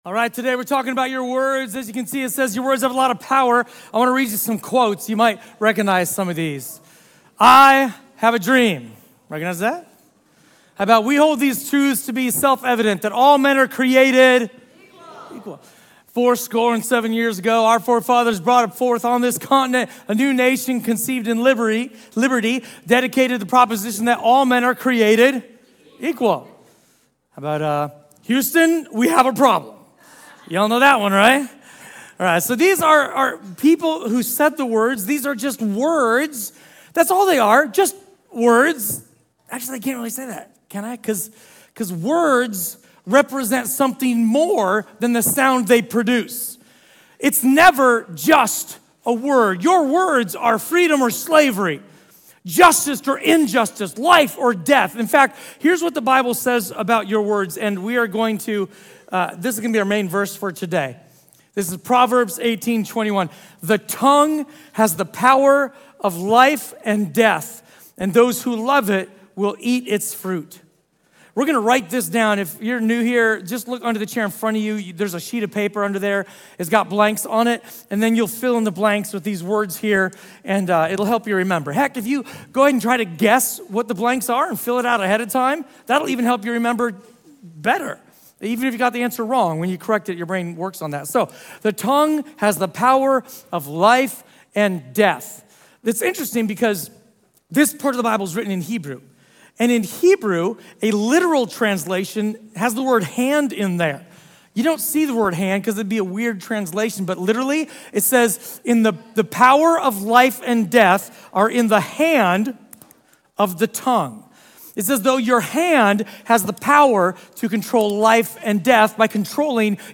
A sermon from the series "Revolution Sermon." Our words show who we really are.